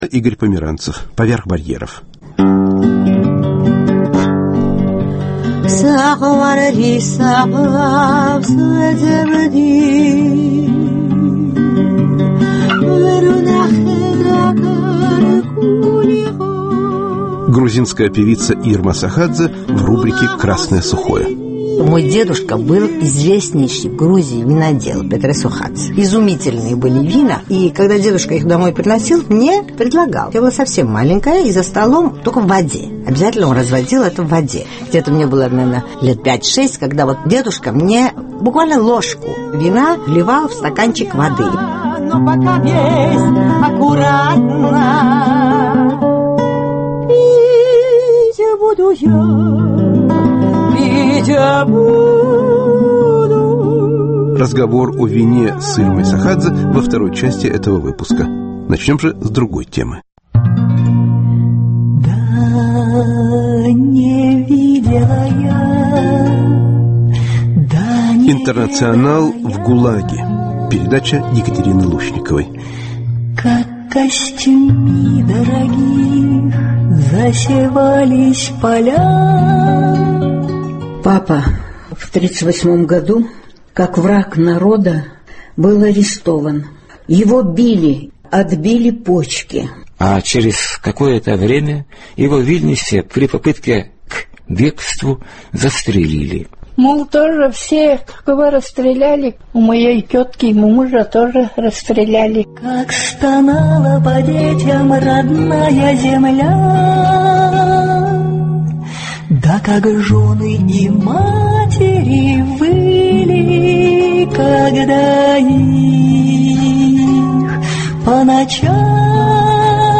Вспоминают бывшие узники разных национальностей